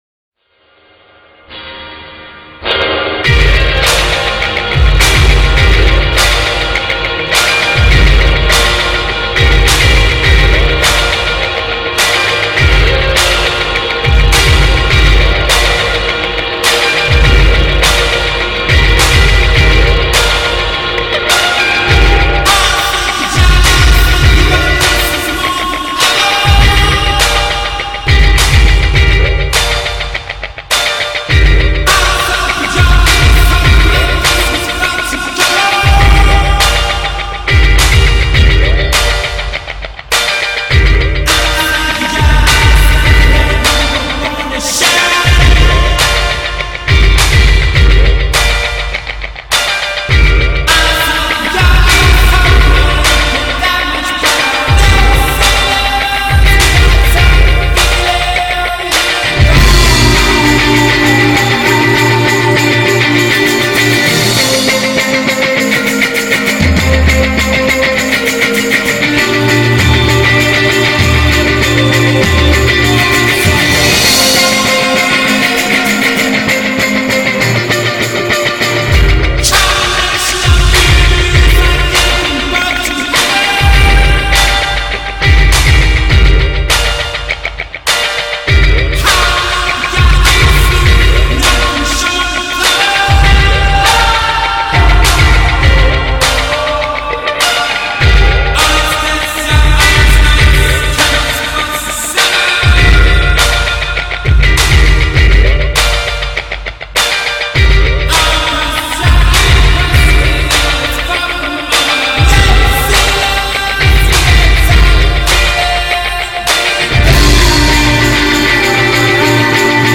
Denver duo
delivers some tasty Indie goodness with their single